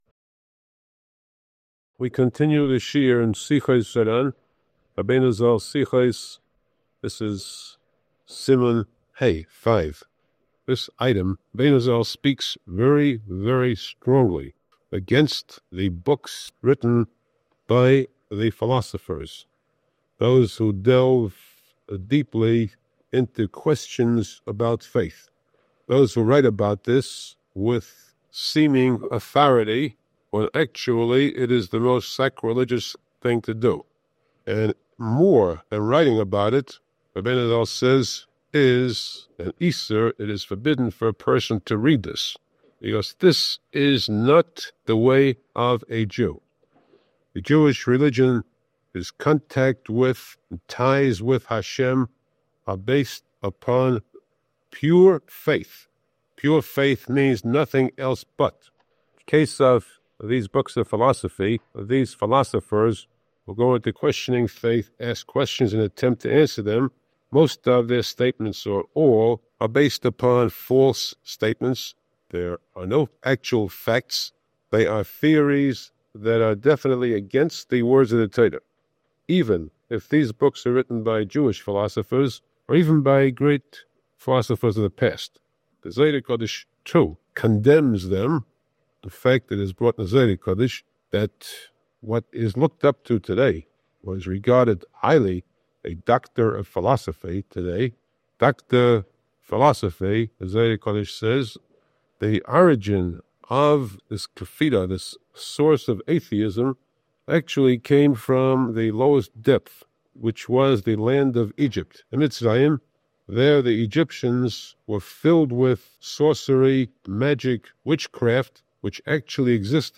Podcast (shiurim): Download (Duration: 45:46 — 21.0MB)